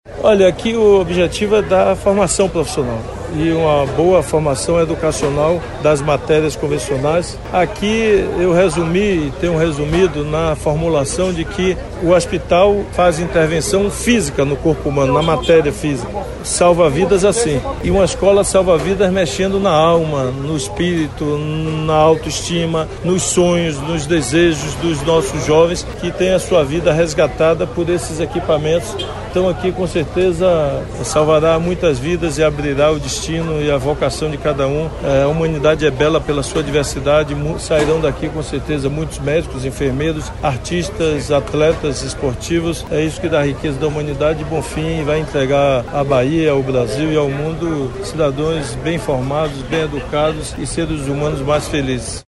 🔊 Sonora Governador Rui Costa